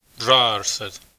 Pronunciation[ˈrˠa.arˠs̪əɾʲ]